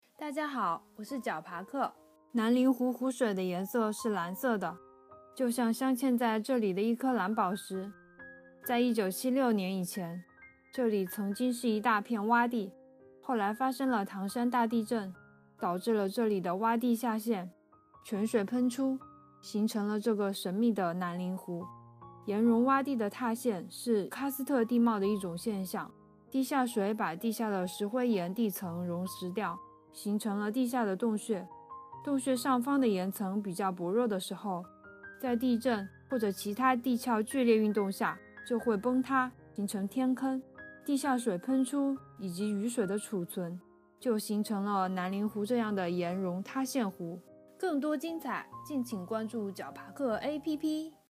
南陵湖----- fin 解说词: 南陵湖位于南陵湖村山顶，海拔约212米，湖水面积约8000平方米，水深1-3米，水色微蓝。